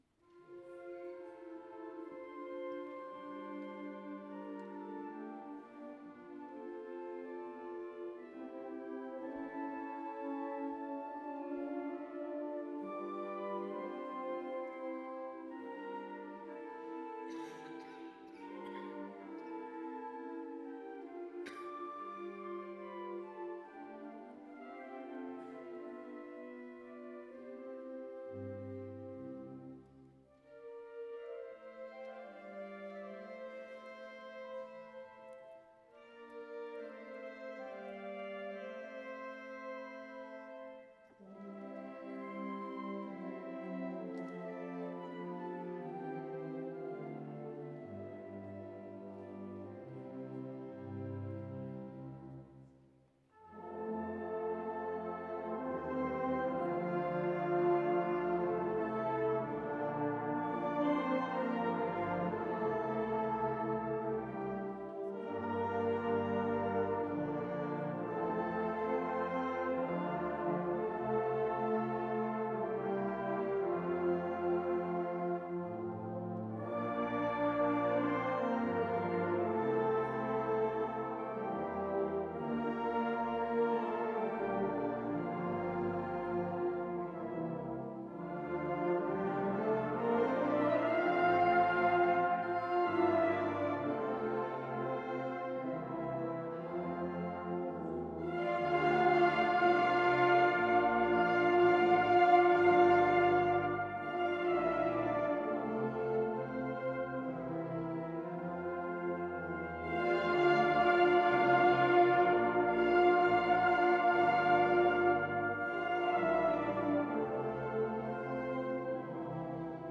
Mit weihnachtlichen Klängen füllten wir am dritten Adventssonntag die Hückeswagener Paulus Kirche.
Ein paar Stücke haben wir auf dem Konzert aufgenommen und unten als Hörprobe für euch zur Verfügung gestellt.